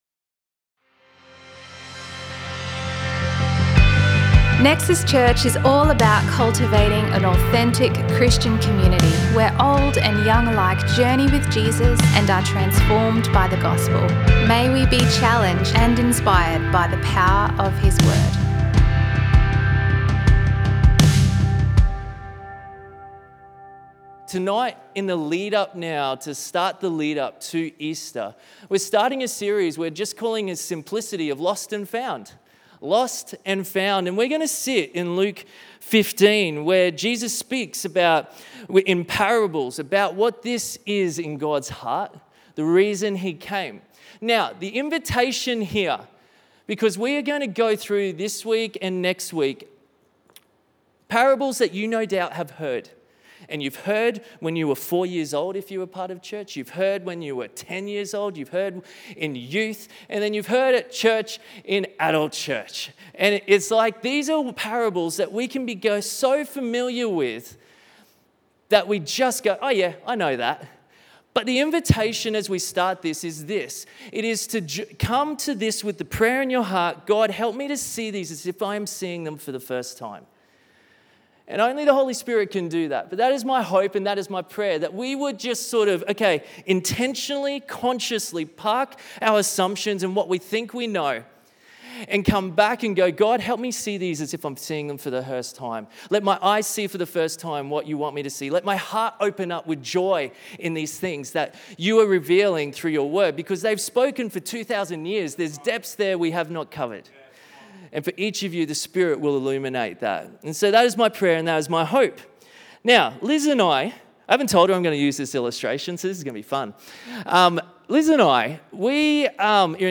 A selection of messages from Nexus Church in Brisbane, Australia.